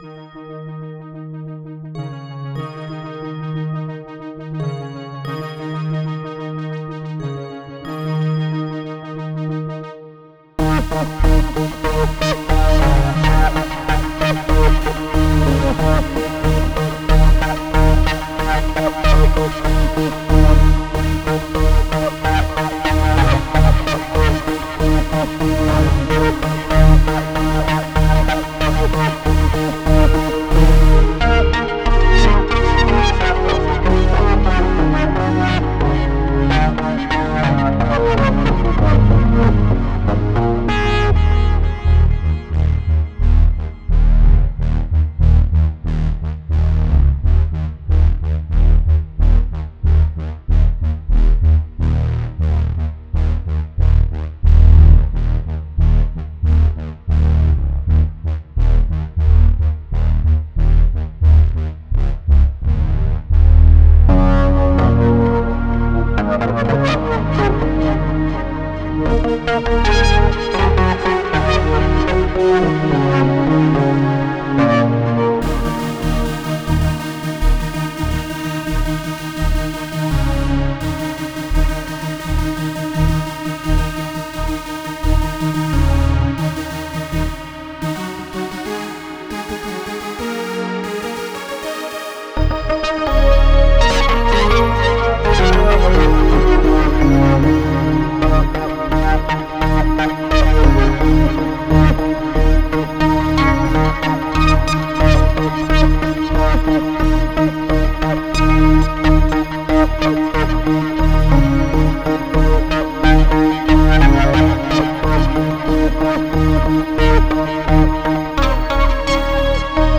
Tense parts and more relaxing parts exist in this piece.